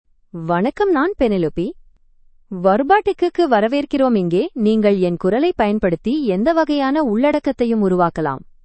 FemaleTamil (India)
Penelope — Female Tamil AI voice
Voice sample
Female
Penelope delivers clear pronunciation with authentic India Tamil intonation, making your content sound professionally produced.